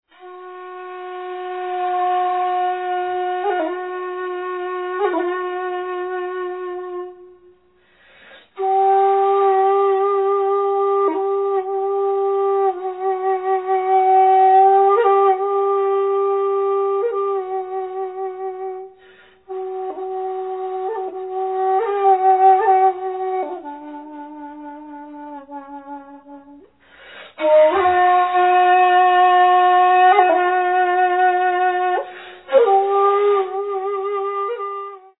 serene solo pieces